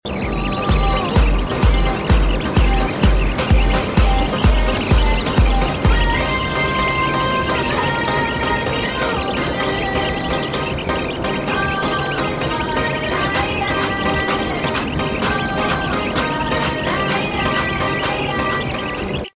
Esse subliminal contém mais de 10 mil afirmações.